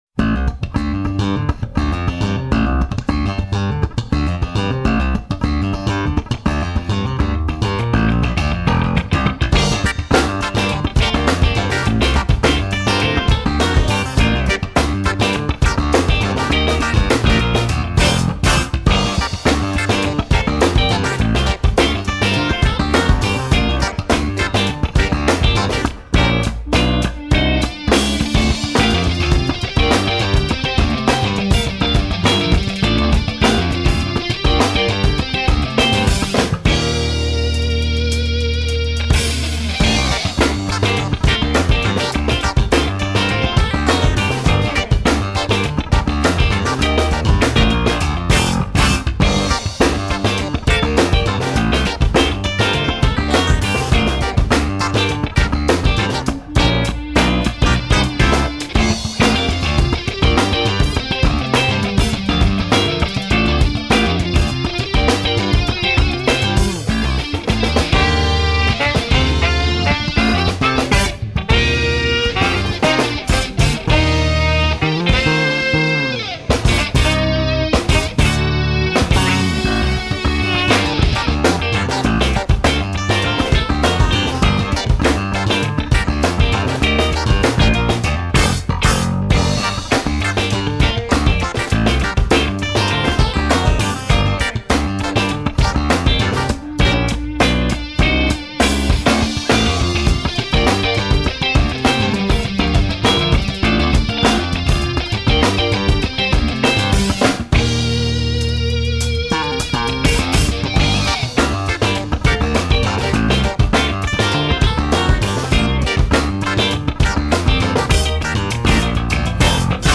im Wki-Studio in Bad Münstereifel
Saxophon